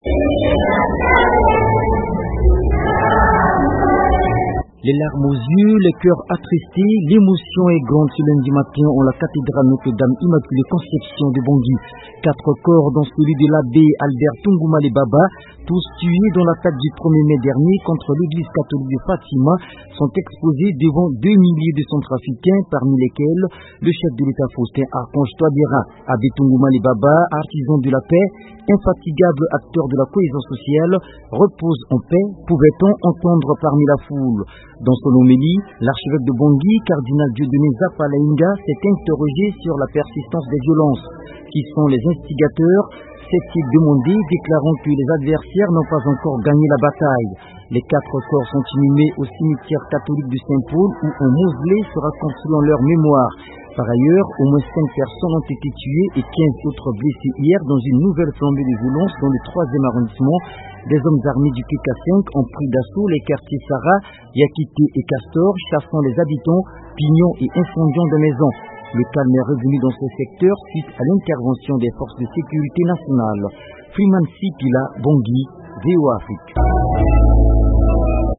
Les larmes aux yeux, les cœurs attristés, l'émotion est grande en la Cathédrale Notre-Dame-Immaculée-Conception de Bangui.
Reportage